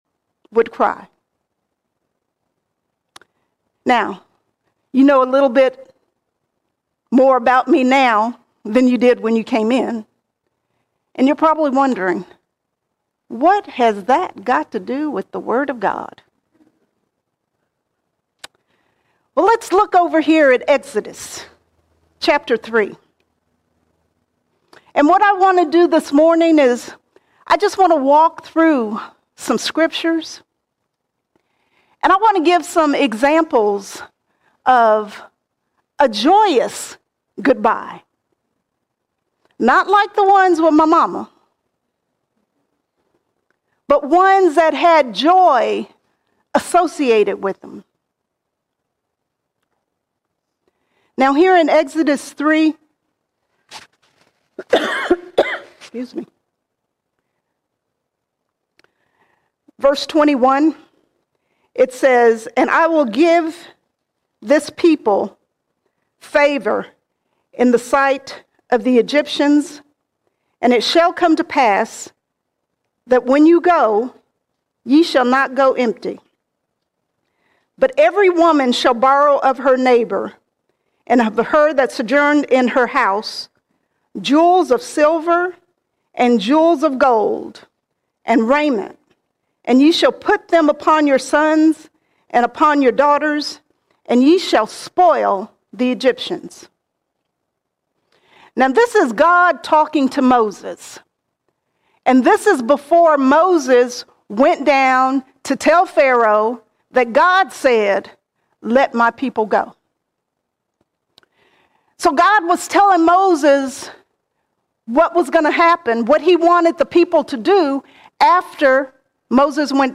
17 March 2025 Series: Sunday Sermons All Sermons A Joyous Goodbye A Joyous Goodbye Jesus' sacrifice grants us a joyous Goodbye, so we rejoice knowing death is not the end!